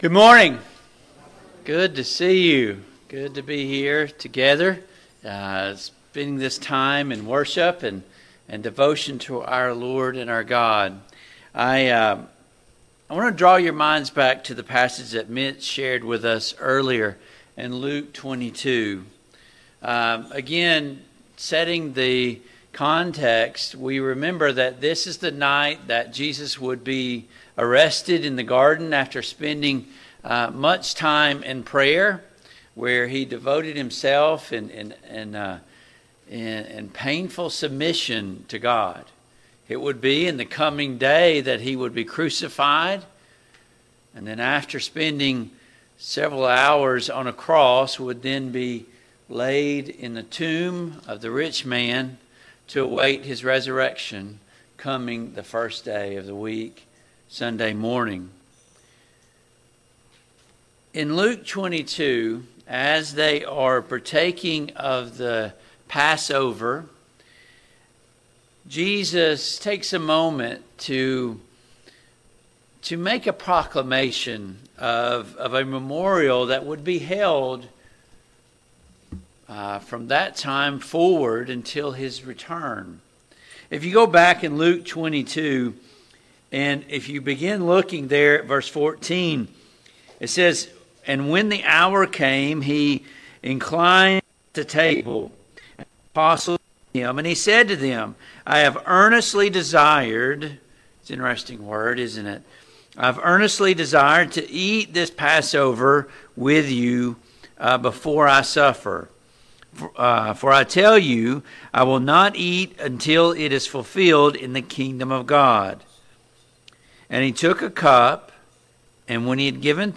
1 Corinthians 11:23-26 Service Type: AM Worship Download Files Notes Topics